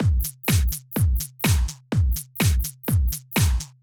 Drumloop 125bpm 10-A.wav